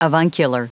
Prononciation du mot avuncular en anglais (fichier audio)
Prononciation du mot : avuncular